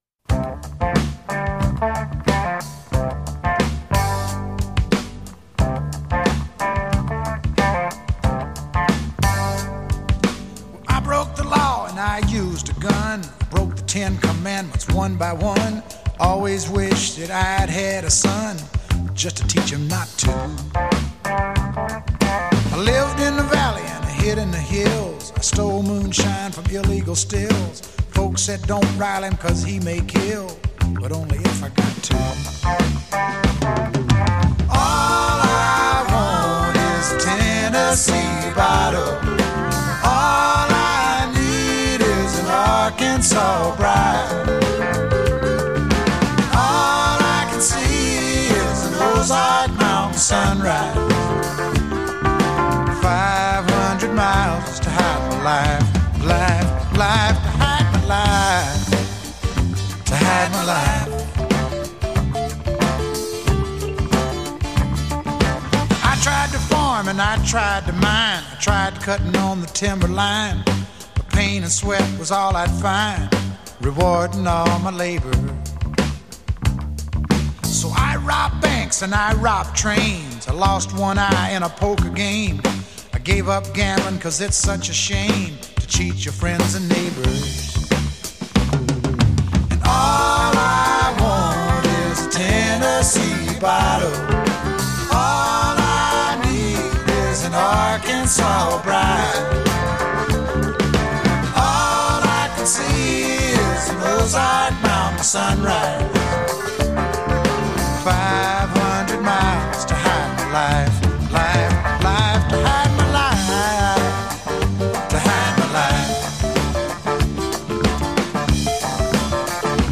The album mixed country and pop sounds